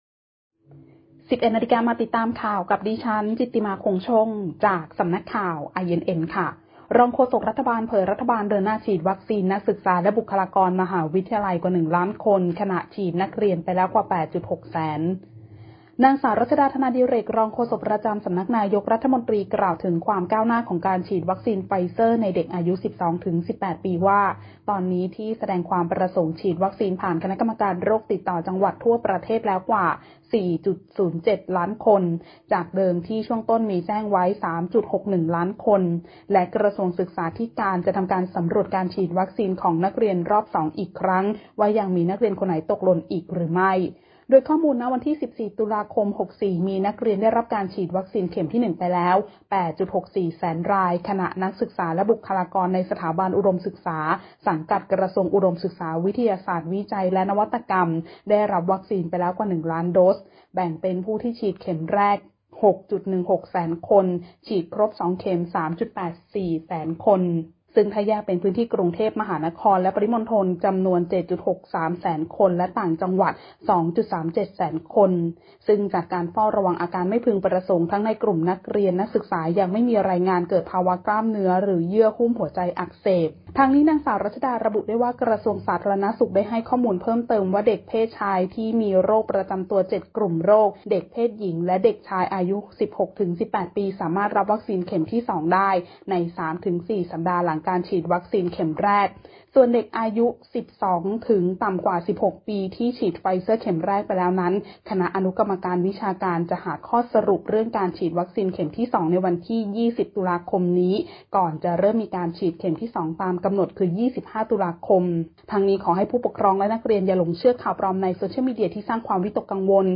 คลิปข่าวต้นชั่วโมง
ข่าวต้นชั่วโมง 11.00 น.